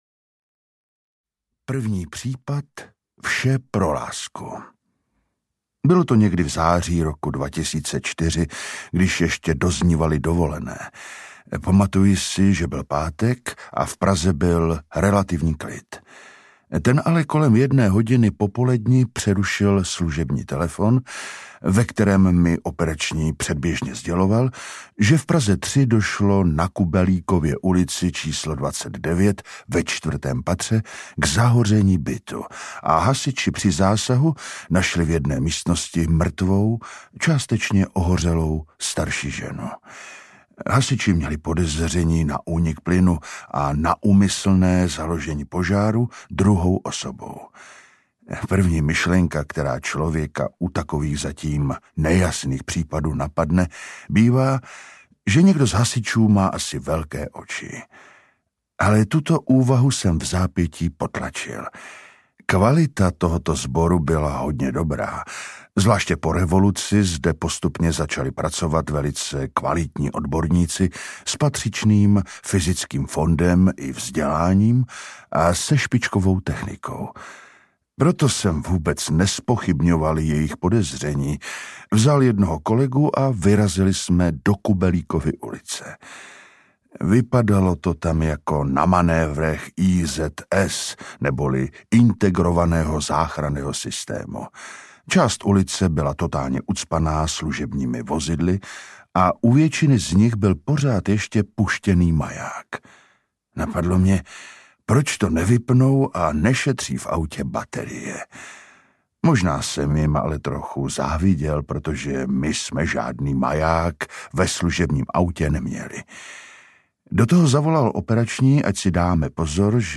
Vrah je v každém z nás 2 audiokniha
Ukázka z knihy